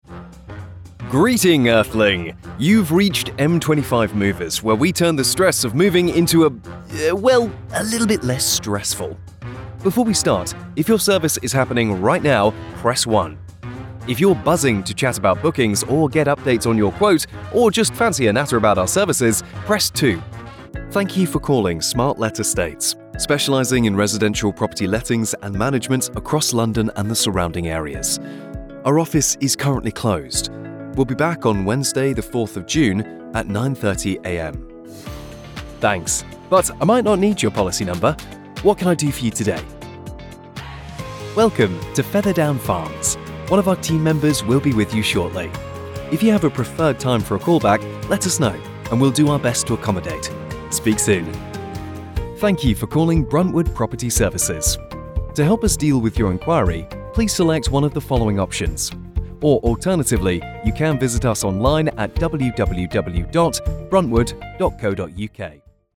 Commerciale, Naturelle, Amicale, Chaude, Polyvalente
Téléphonie